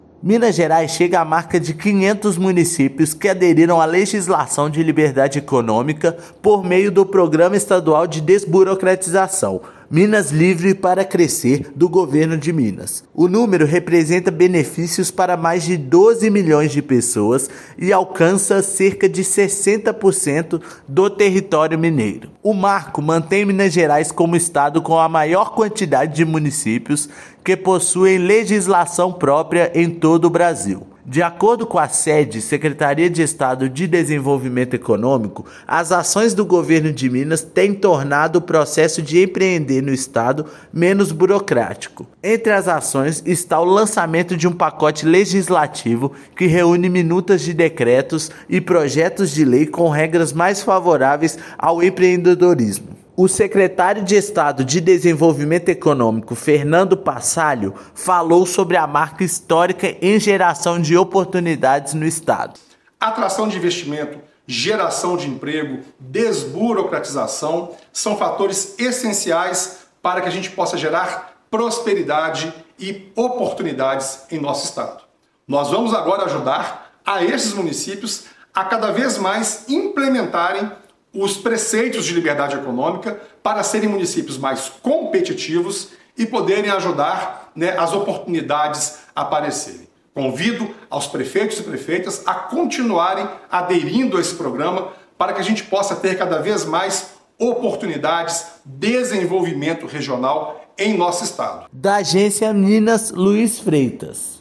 Estado se mantém como o maior do país em número de cidades que já implementaram legislação própria. Ouça matéria de rádio.